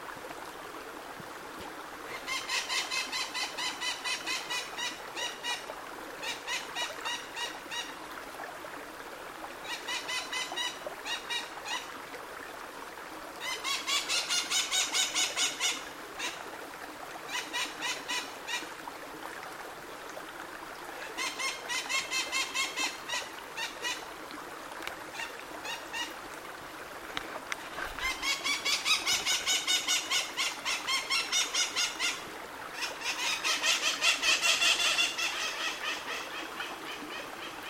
Periquitão-maracanã (Psittacara leucophthalmus)
Classe: Aves
Nome em Inglês: White-eyed Parakeet
Província / Departamento: Misiones
Localidade ou área protegida: Bio Reserva Karadya
Condição: Selvagem
Certeza: Gravado Vocal